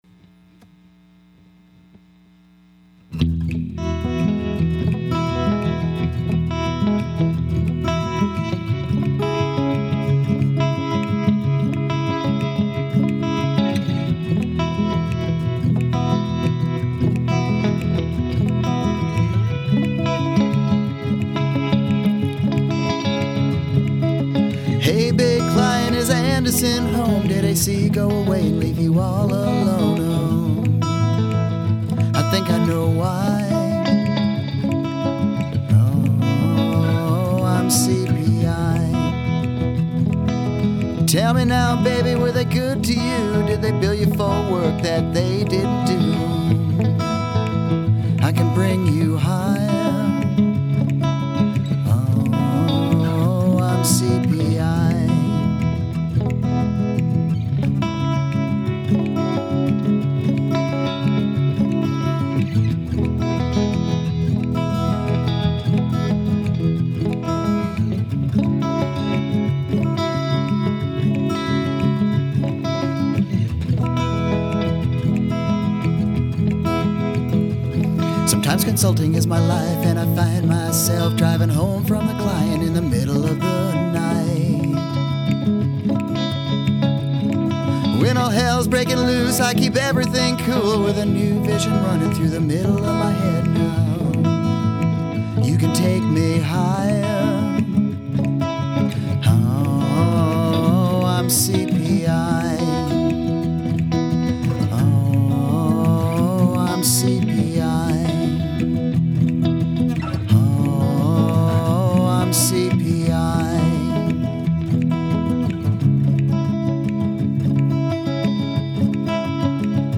Lastly, here’s some old CPI/PW music we had during the party, along with the words to the song we sang.